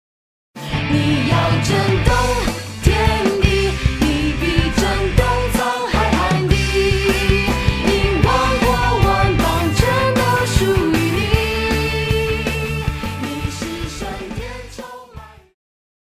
Christian
Vocal - female,Vocal - male
Band
POP,Christian Music
Instrumental
Voice with accompaniment